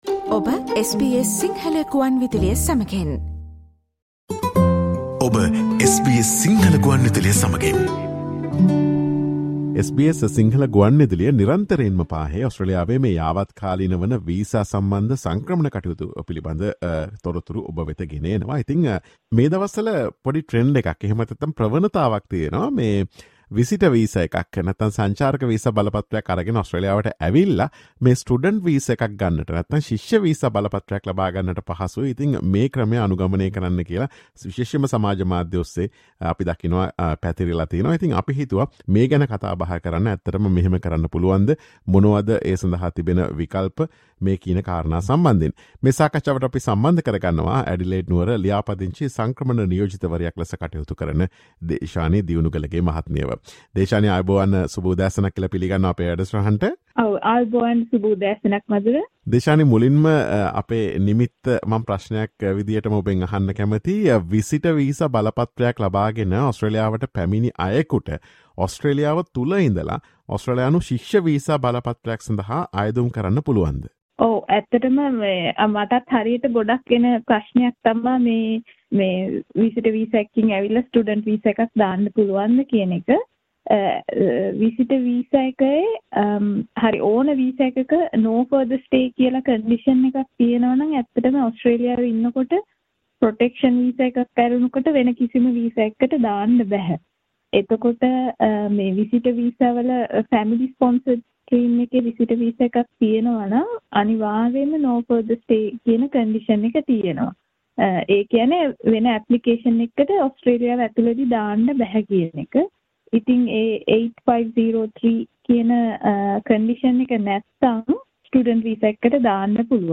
A big trend is spreading these days that you can come to Australia with a visitor visa and apply for a student visa. Listen to this SBS Sinhala Radio discussion to know the truth.